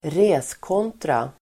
reskontra substantiv, ledgerUttal: [²resk'ån:tra] Böjningar: reskontran, reskontrorDefinition: bokföringsbok med ett konto för varje kund
reskontra.mp3